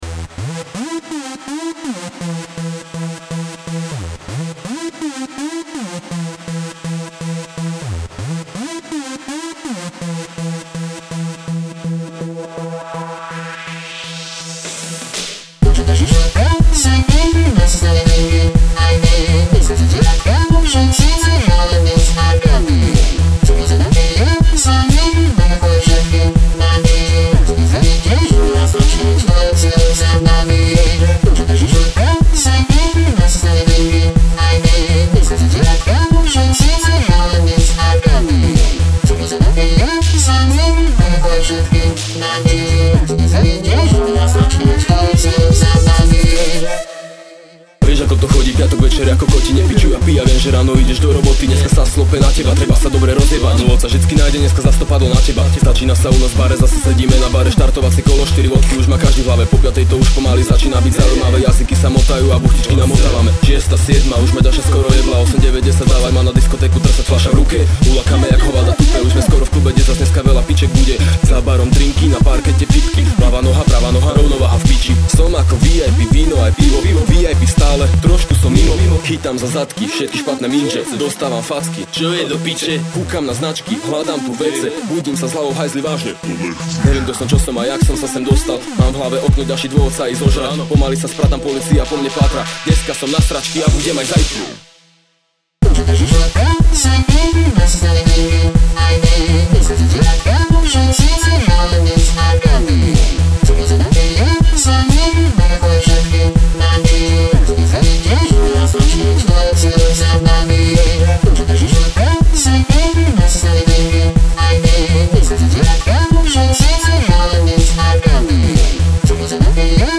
O tanečný remix